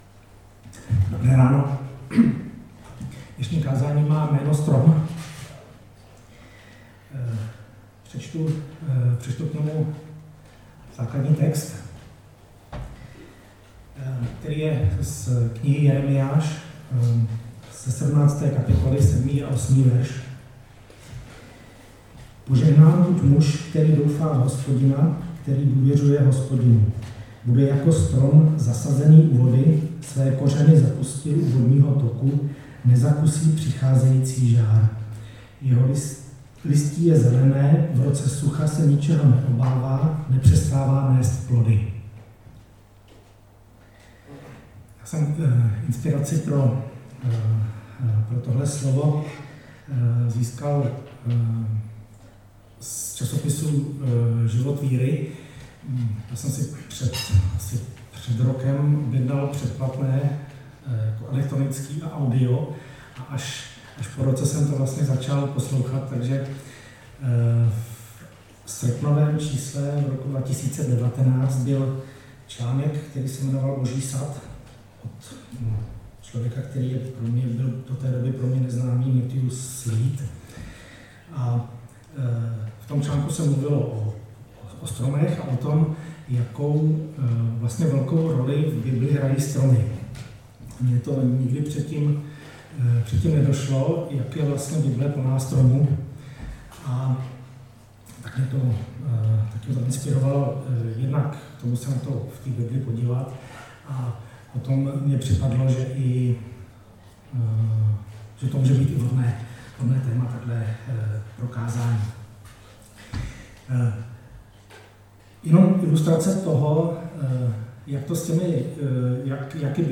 Nedělní bohoslužby